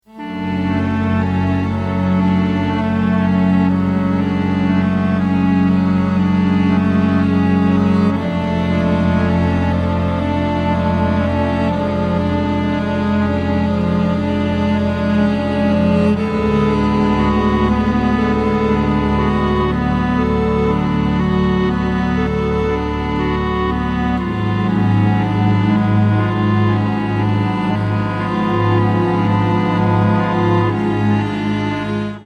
Категория: Музыка из фильмов ужасов